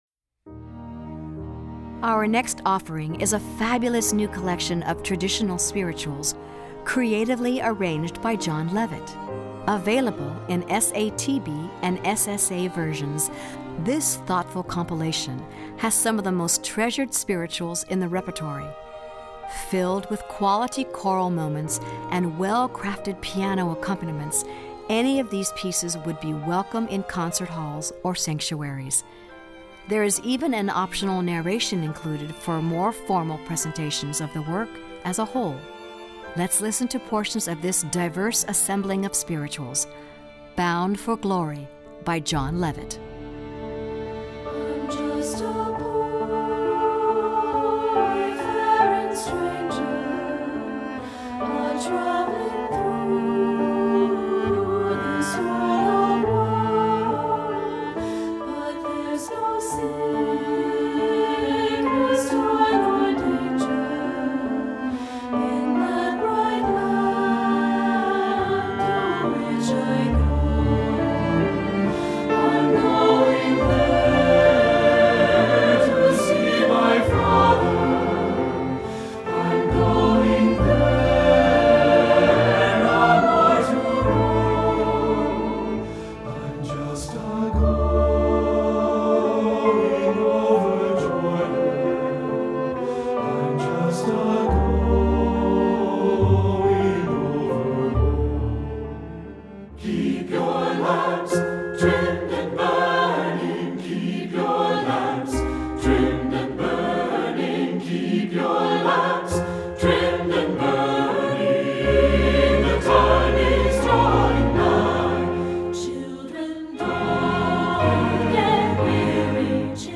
Composer: Spirituals
Voicing: SATB and Piano